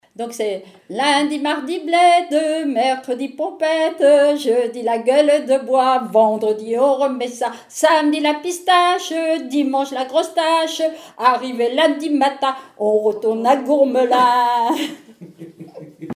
Témoignages et chants brefs
Pièce musicale inédite